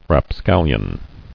[rap·scal·lion]